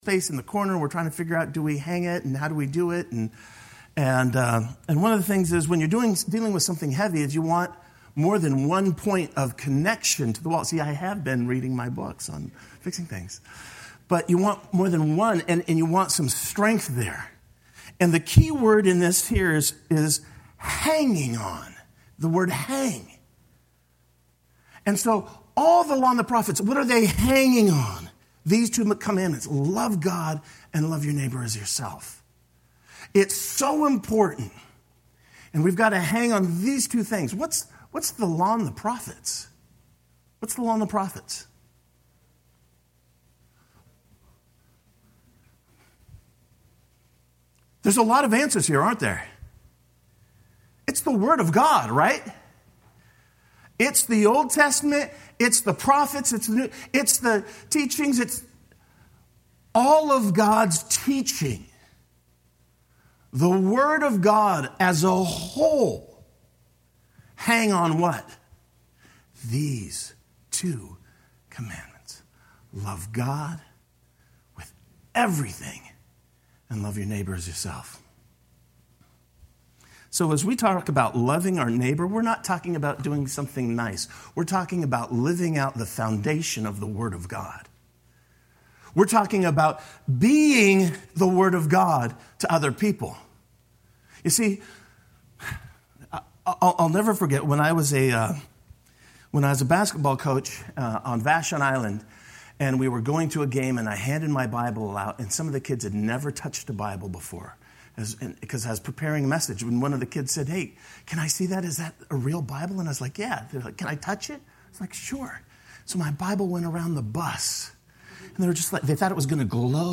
Sermon-11-6.mp3